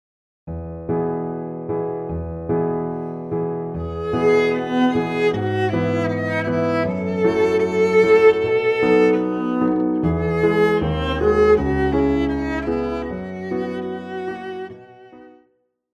Classical
Viola
Piano
Instrumental
Solo with accompaniment
Here we have an arrangement for viola and piano.